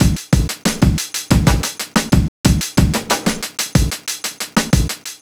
Back Alley Cat (Drums 01).wav